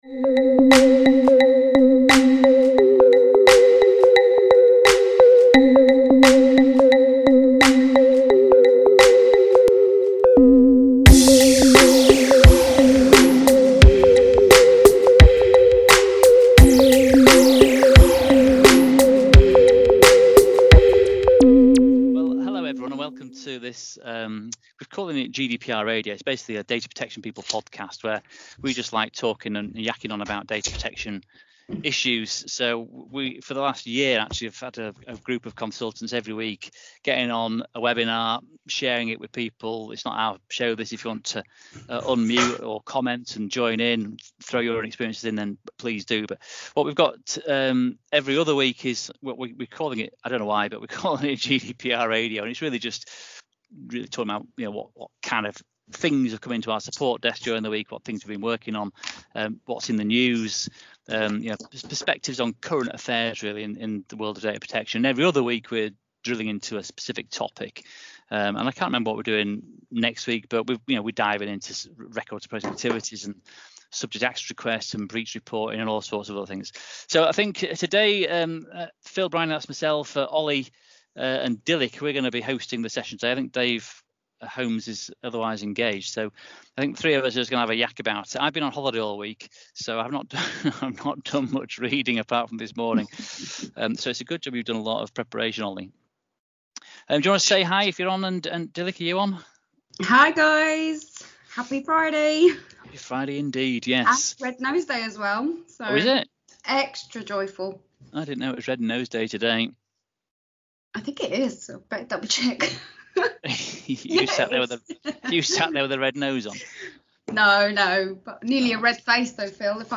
have a discussion about recent news